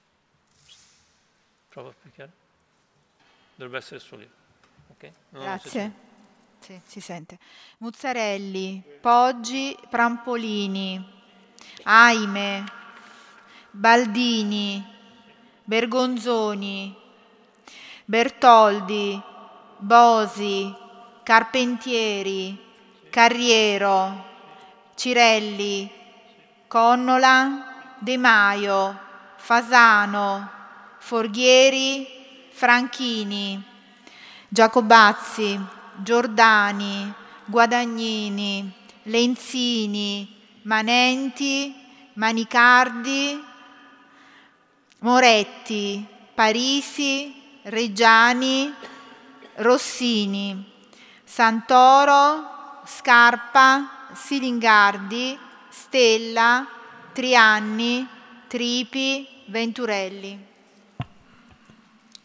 Seduta dell' 11/06/2020 Appello.
Segretaria